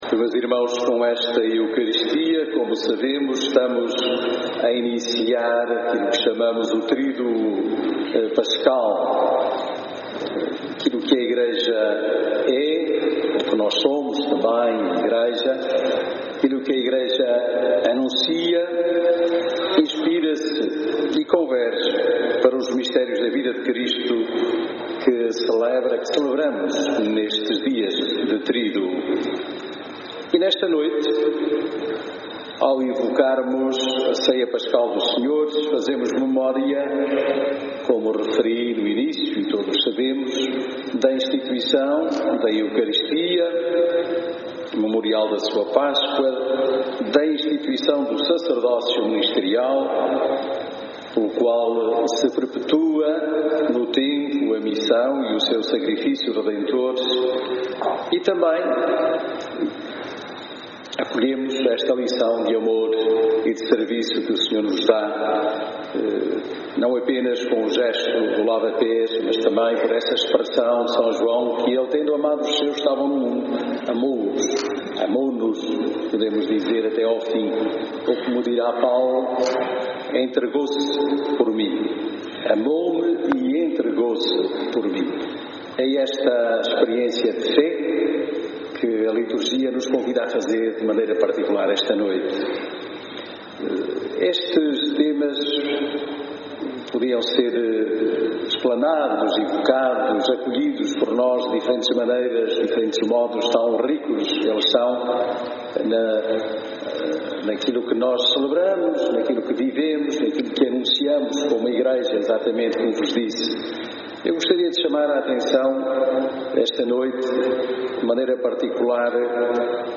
Homilia_missa_ceia_senhor_2018.mp3